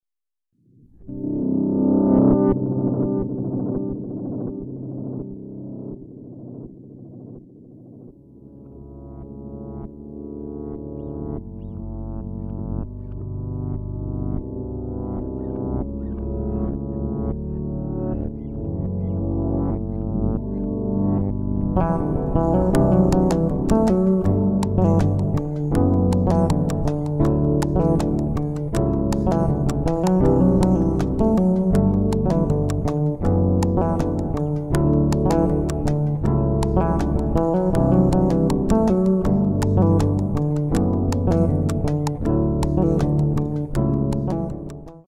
bassist and composer
on drums
on keyboards
on alto saxophone